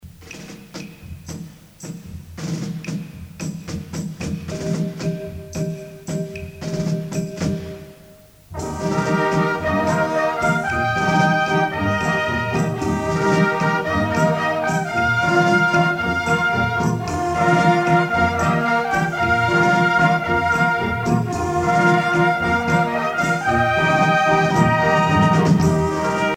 danse : rumba
circonstance : militaire
Pièce musicale éditée